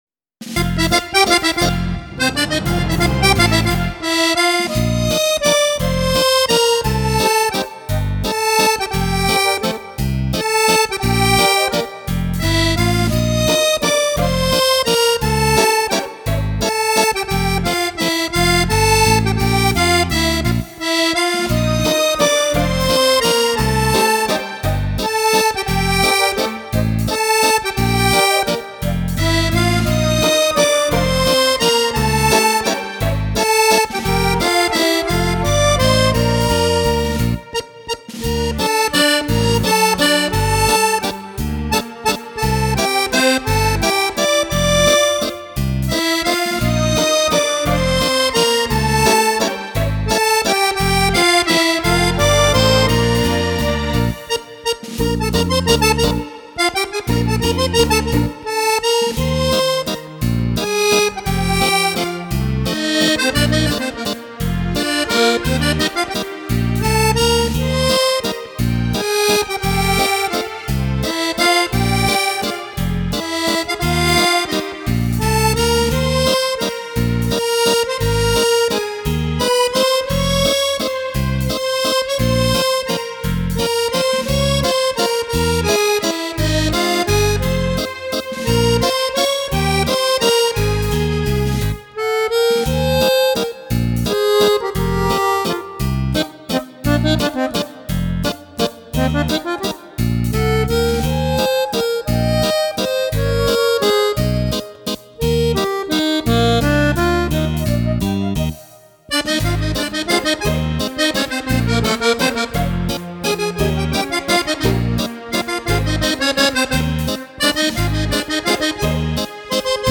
Valzer Viennese
14 ballabili per Fisarmonica  di facile esecuzione.